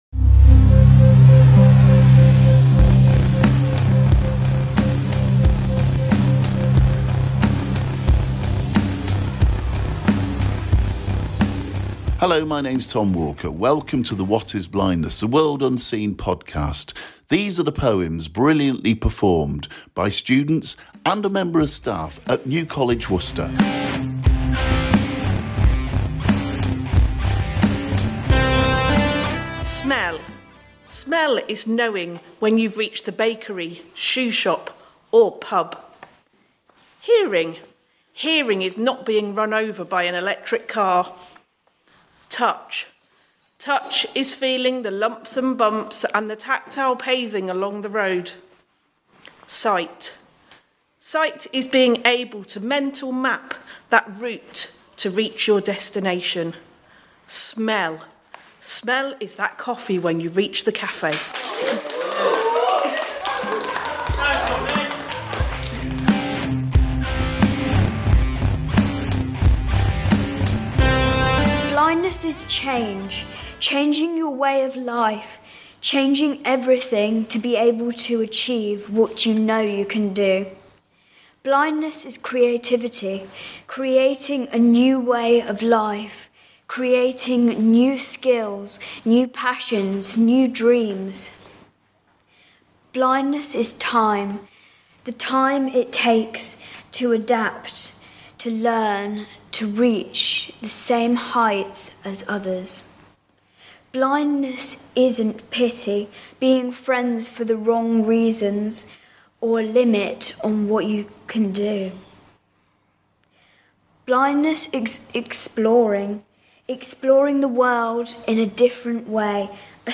featuring a moving collection of poems read by staff and students who took part in the project. https